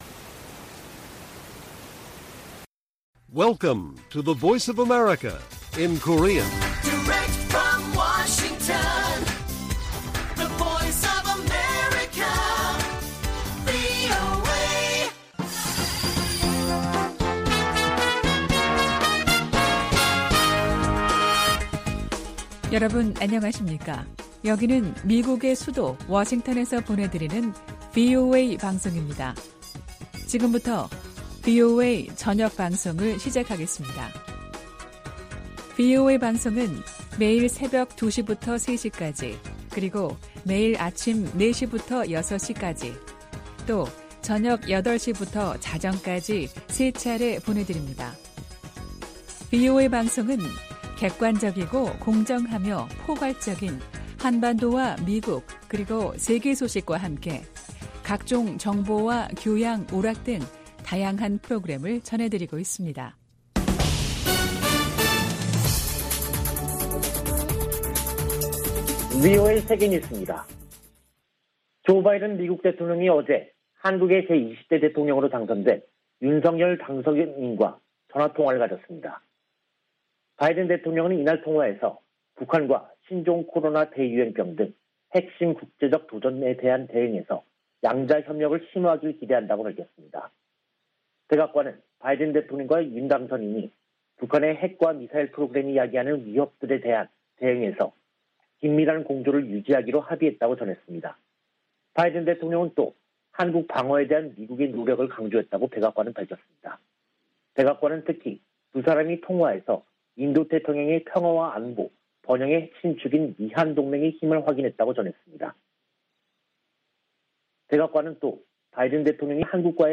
VOA 한국어 간판 뉴스 프로그램 '뉴스 투데이', 2022년 3월 10일 1부 방송입니다. 한국 대통령 선거에서 윤석열 후보가 승리했습니다.